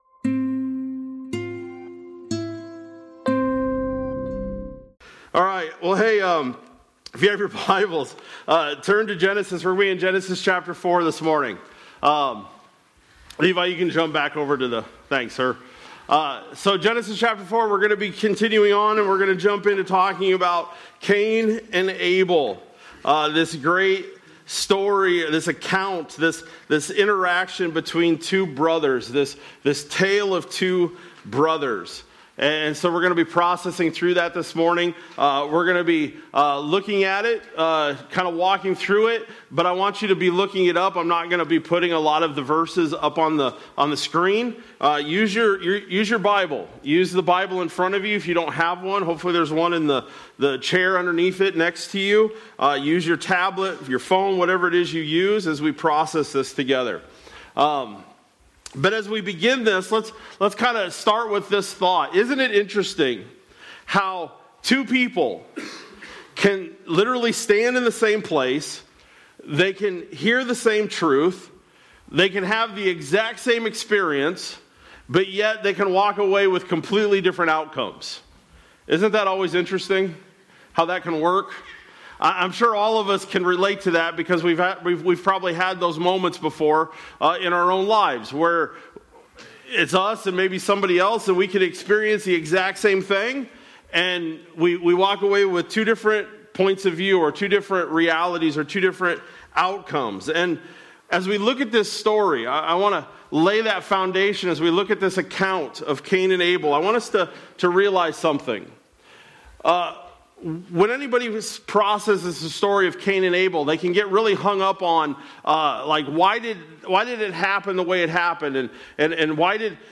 March-1-Sermon-Audio.mp3